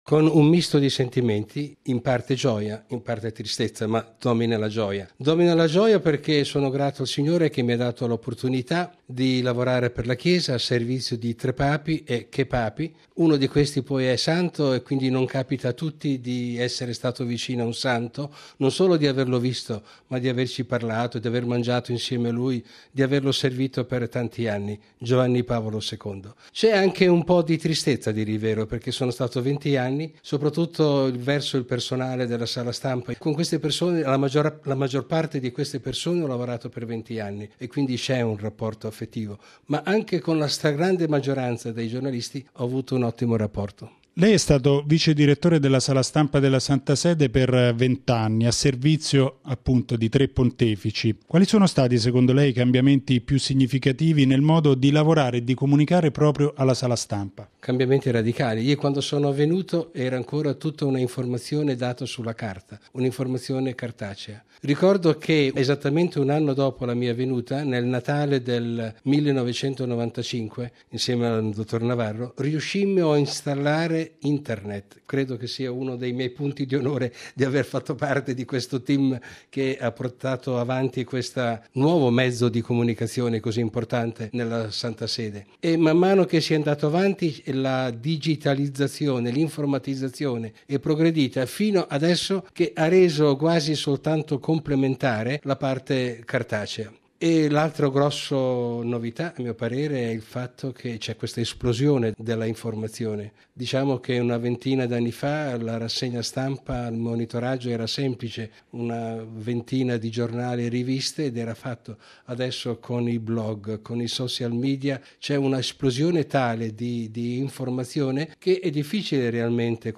Nell’intervista